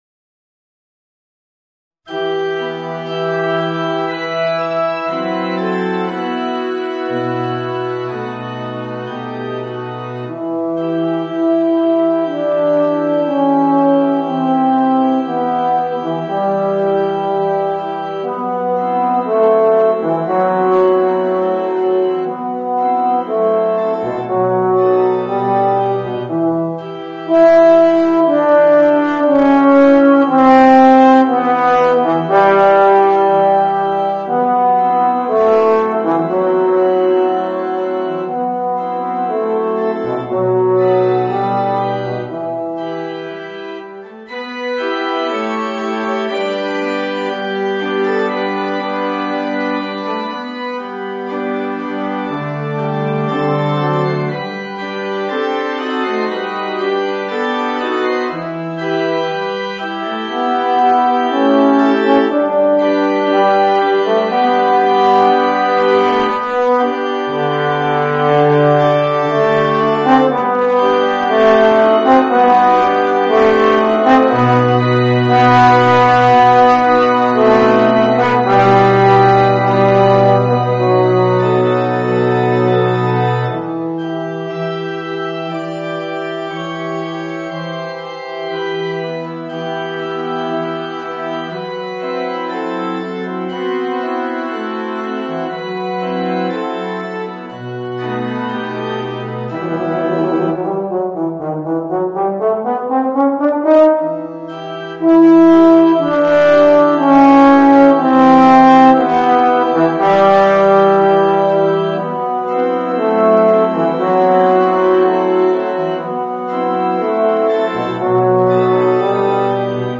Voicing: Euphonium and Organ